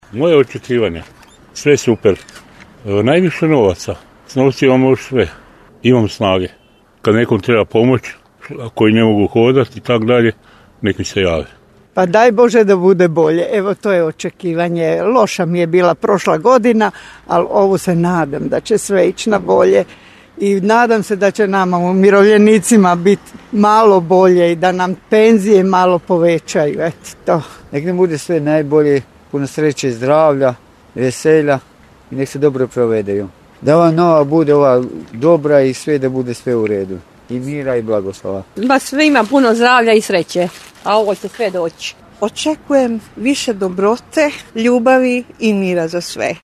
Želje su univerzalne a očekivanja realna, ljudska. Poslušajte naše sugrađane koje smo sasvim slučajno sreli, zaustavili i podijelili njihove želje i očekivanja za sve vas u Novoj 2025. godini.
Anketa